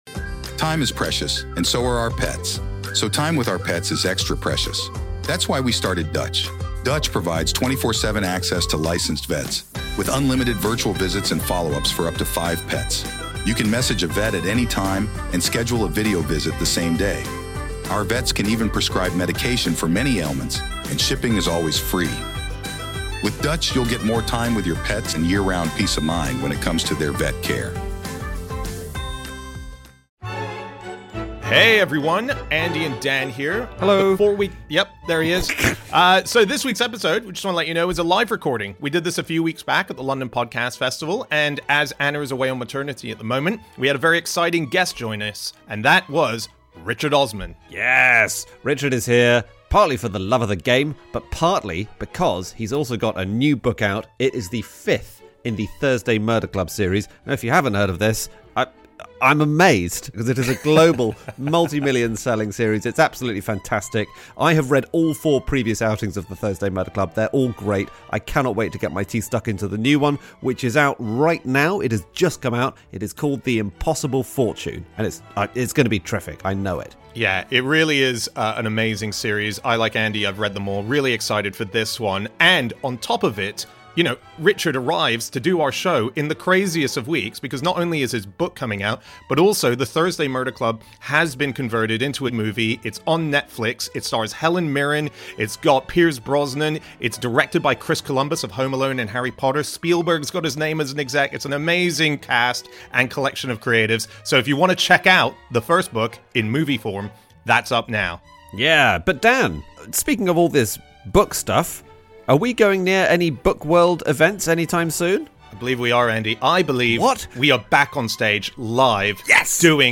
Live from the London Podcast Festival